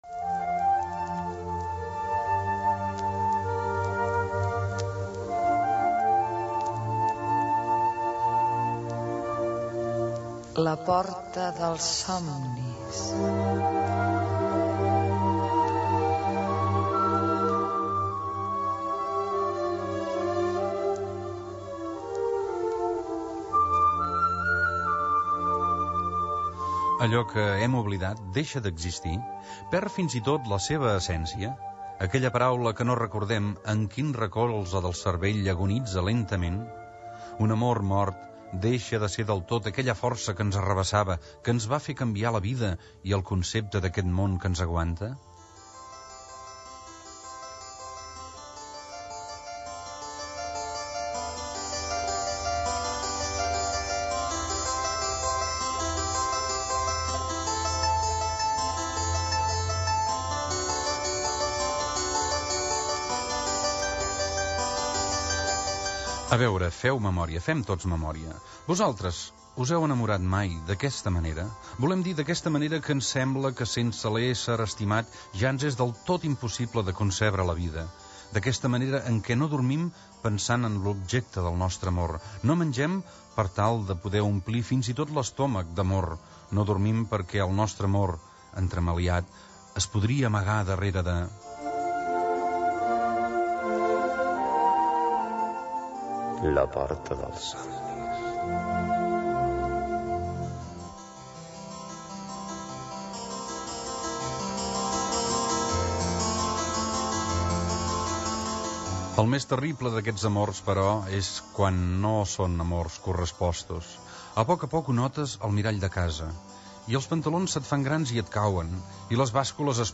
Careta del progra, introducció sobre l'enamorament. L'obra de Marià Manent, poeta, prosista, crític literari